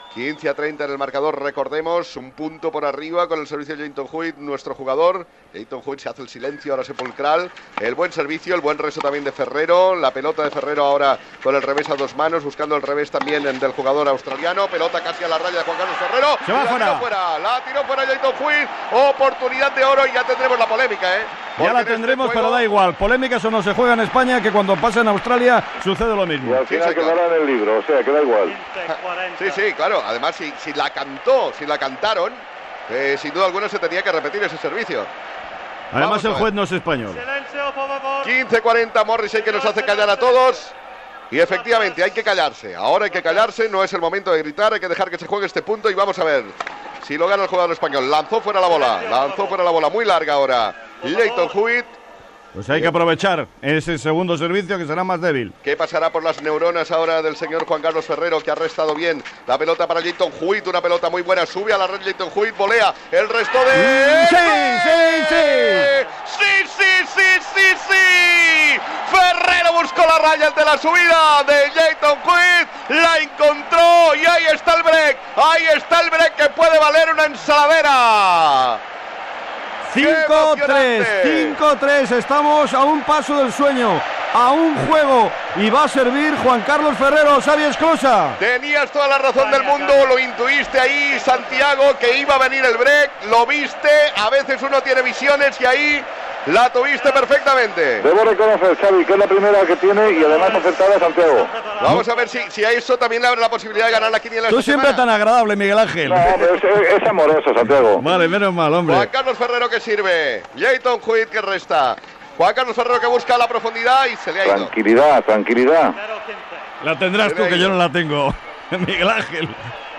Narració de les últimes jugades del partit entre els tennistes Juan Carlos Ferrero i Lleyton Hewitt, amb victòria del primer. Narració des de la pista després del partit. Espanya guanya la Copa Davis de l'any 2000 en superar a l'equip australià per 3-1